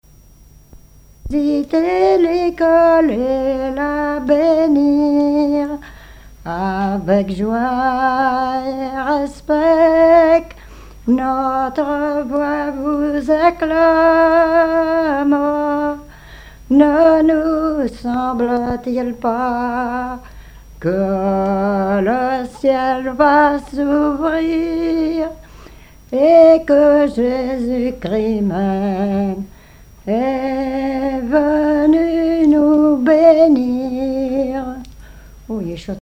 enfantine : prière, cantique
Bribes de chansons ou cantiques
Pièce musicale inédite